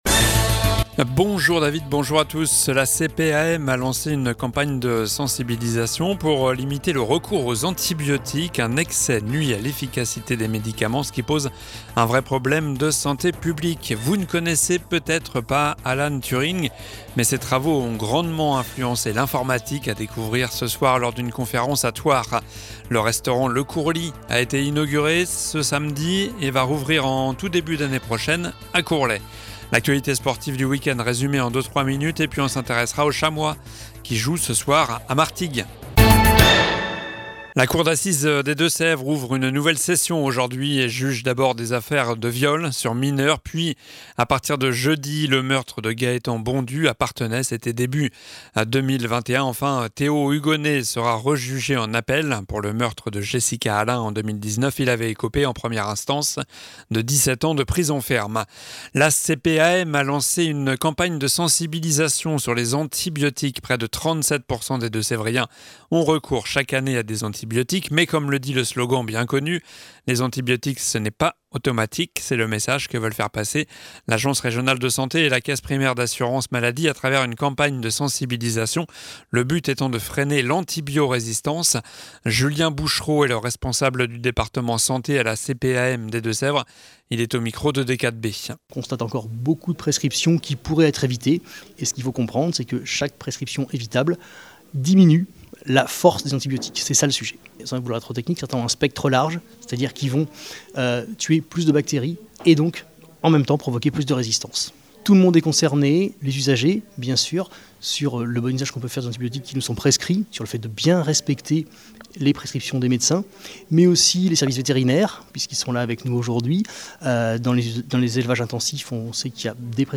Journal du lundi 4 décembre (midi)